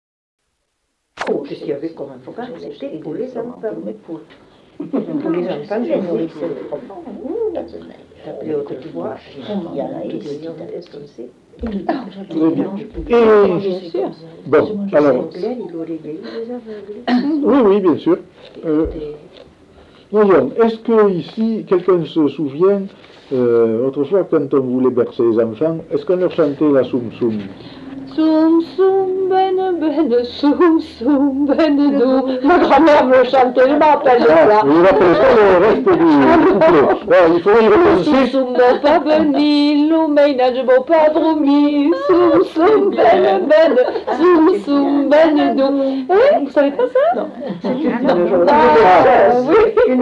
Aire culturelle : Grandes-Landes
Lieu : Salles
Genre : chant
Effectif : 1
Type de voix : voix de femme
Production du son : chanté
Classification : som-soms, nénies